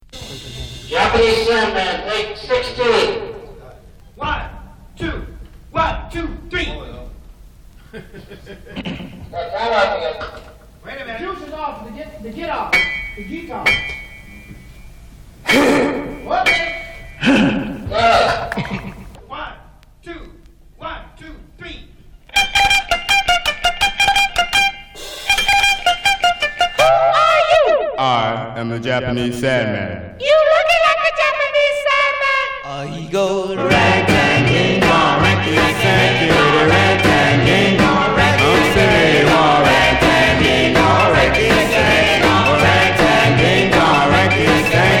アップテンポもバラードもお手のモノ。若さと勢いを感じる録音の数々。曲によりちょっと不安定なボーカルも最高。
Rock’N’Roll, Doo Wop　USA　12inchレコード　33rpm　Mono